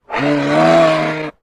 Moose Grunt, Single. Close Perspective.